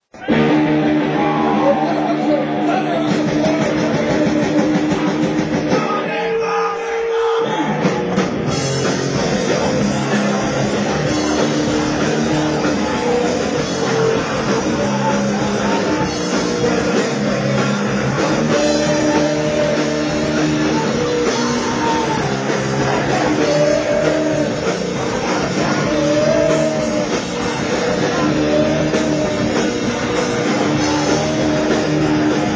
Northshore Surf Club